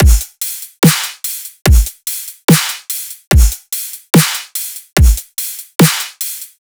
VR_drum_loop_thicksnare_150.wav